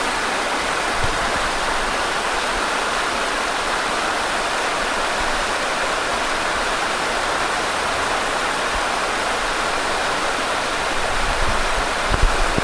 river-1.ogg